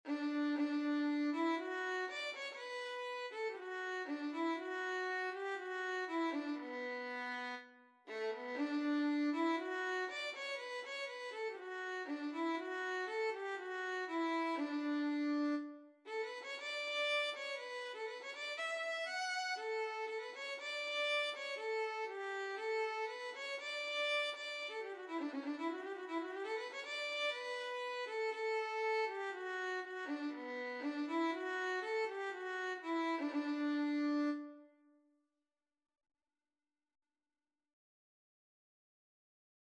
4/4 (View more 4/4 Music)
A4-F#6
D major (Sounding Pitch) (View more D major Music for Violin )
Violin  (View more Intermediate Violin Music)
Traditional (View more Traditional Violin Music)
Irish